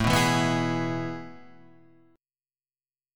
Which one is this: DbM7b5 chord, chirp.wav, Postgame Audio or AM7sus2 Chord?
AM7sus2 Chord